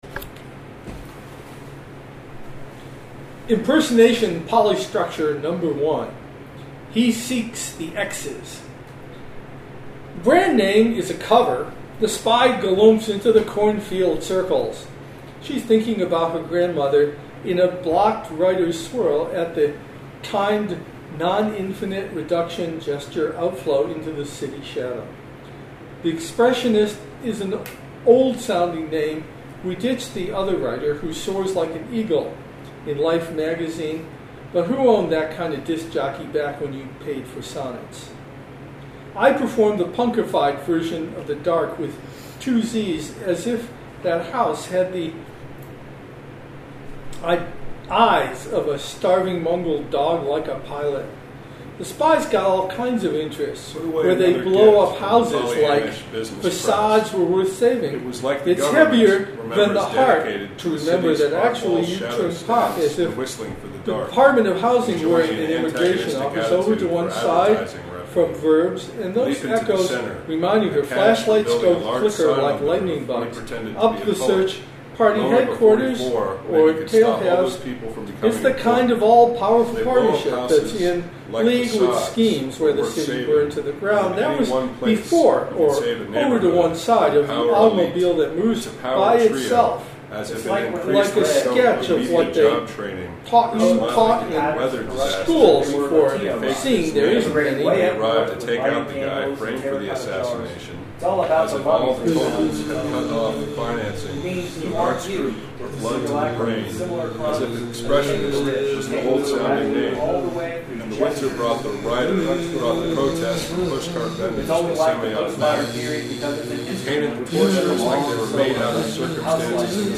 May 12, 2010 Meeting Recordings